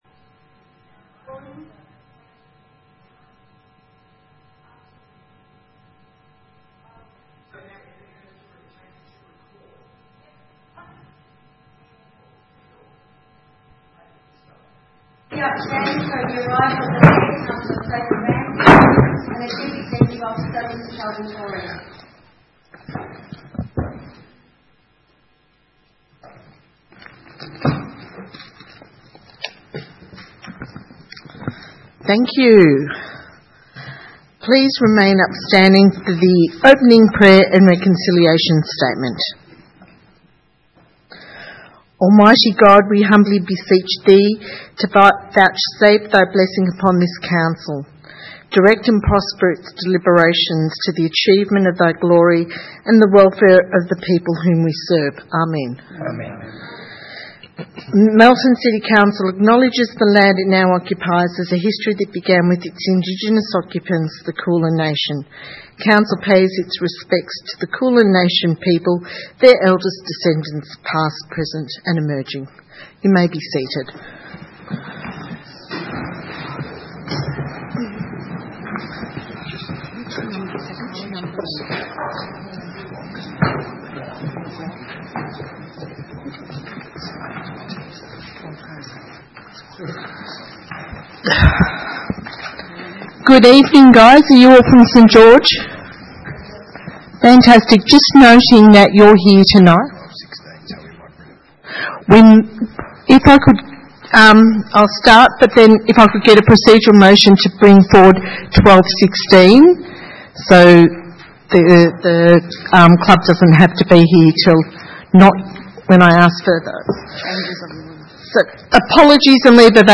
Ordinary meeting